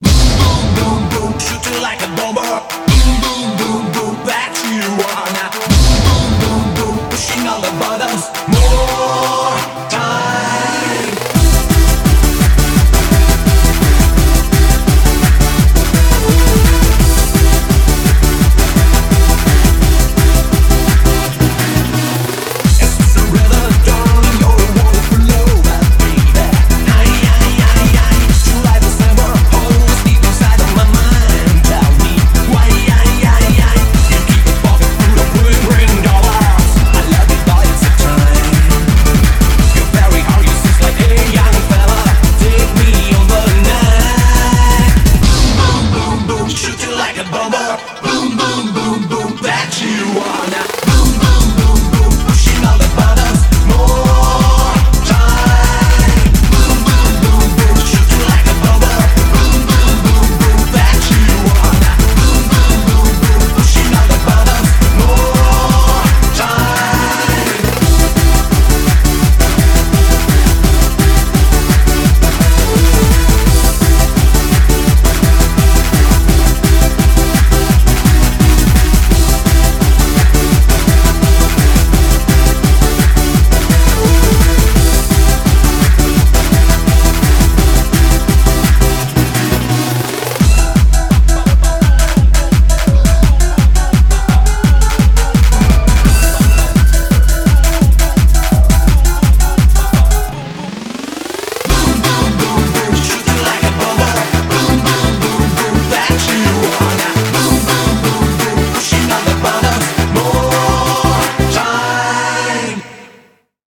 BPM170
Audio QualityMusic Cut